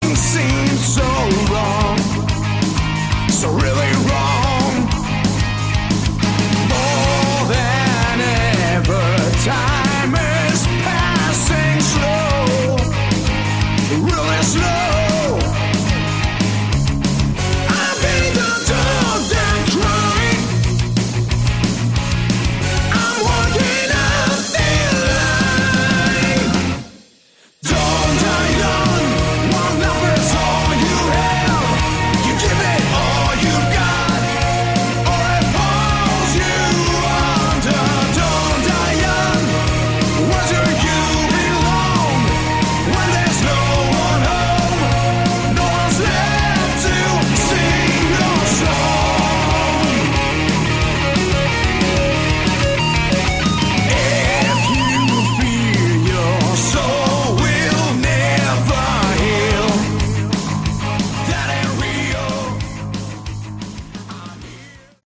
Category: Hard Rock
vocals
guitars
keyboards
bass
drums
Never heard such bad bad voice!!!derrible!!!!